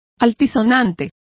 Complete with pronunciation of the translation of grandiloquent.